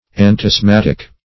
Antiasthmatic \An`ti*asth*mat"ic\, a. & n.